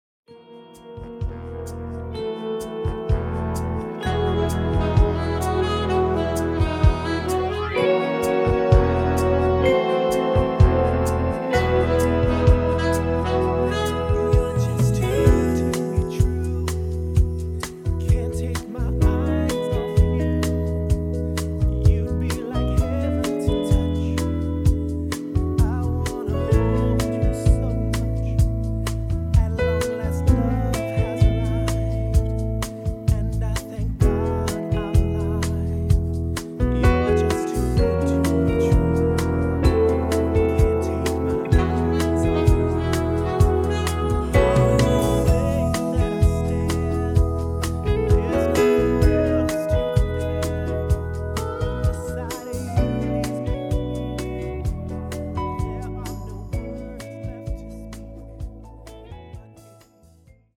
음정 원키 3:17
장르 가요 구분 Voice Cut